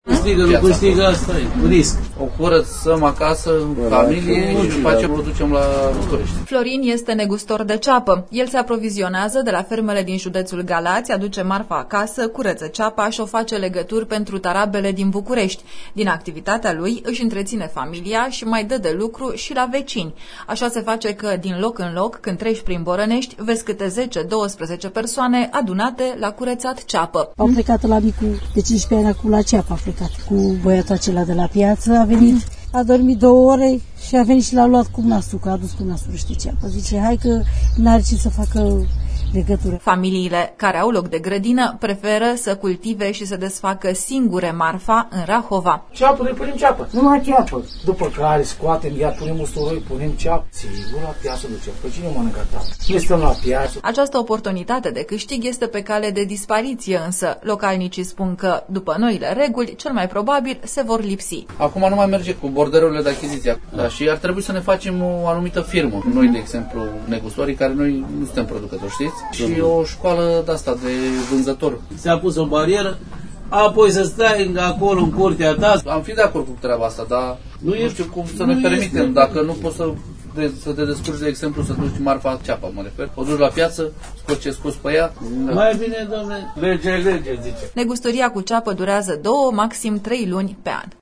audio Reportaj CEAPA